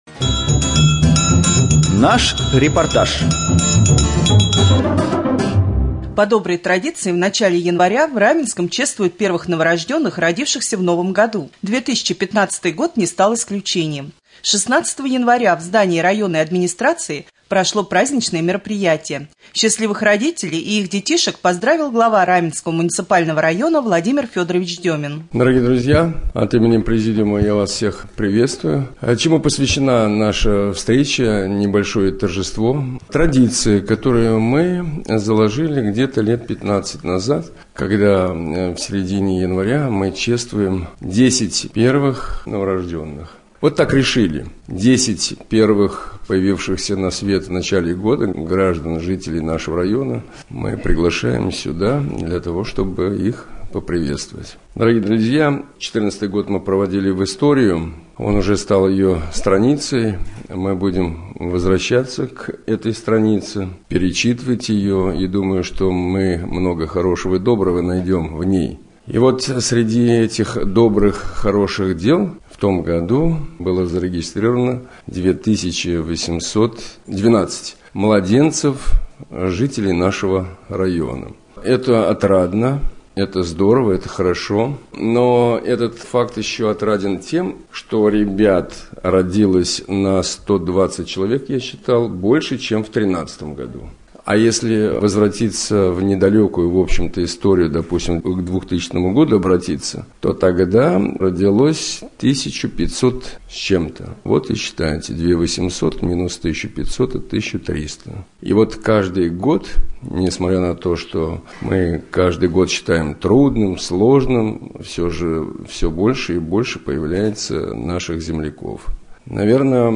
3.Рубрика «Специальный репортаж». В администрации чествовали 10 первых новорожденных 2015 года.